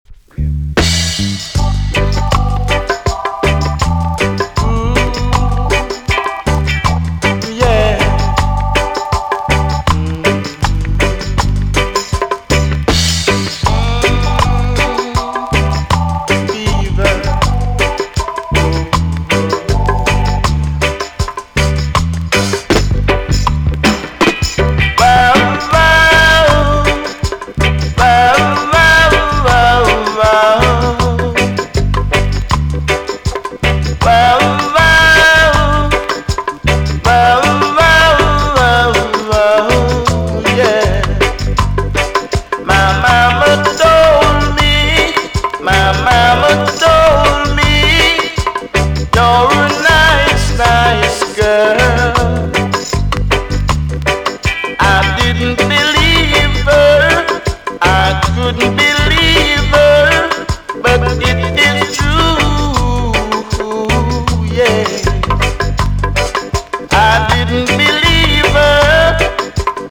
TOP >80'S 90'S DANCEHALL
EX-~VG+ 少し軽いチリノイズが入ります。
NICE VOCAL TUNE!!